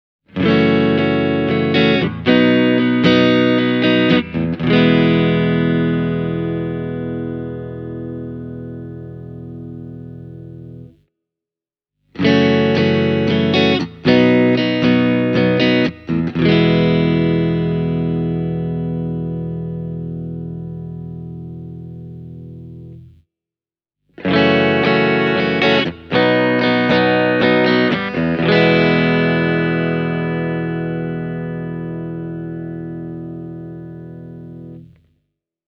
On clean amp settings you will get a clear attack to each note, plenty of chime, and superb string separation (this clip starts with the neck pickup):
squier-cabronita-telecaster-e28093-clean.mp3